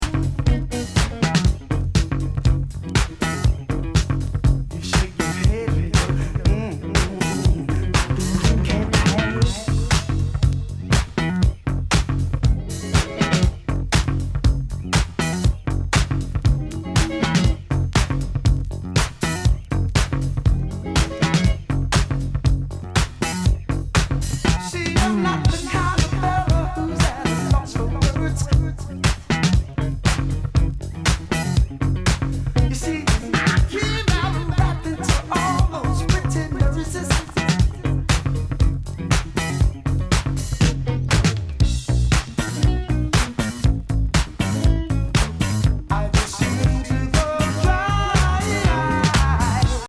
シカゴ・ハウスの裾野的？
エロ・アシッディ